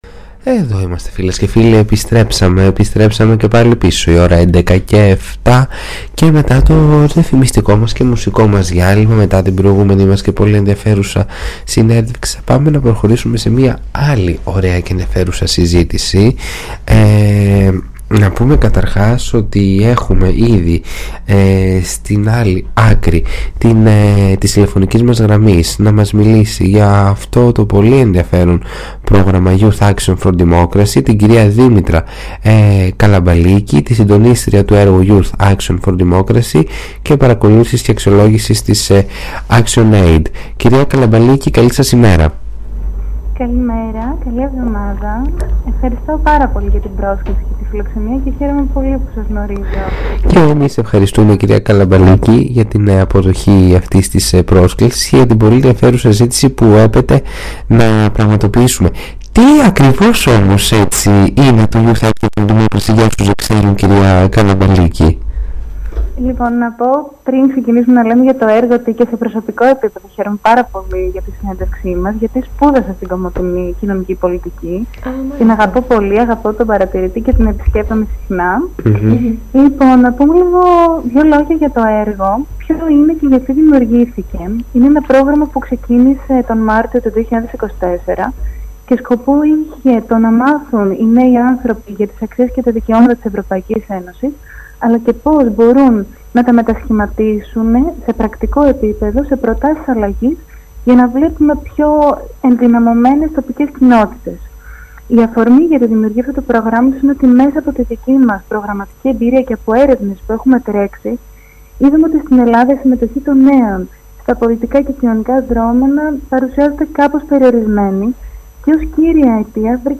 Συνέντευξη στο Ράδιο Παρατηρητής: Μια συζήτηση για το πρόγραμμα Youth Action For Democracy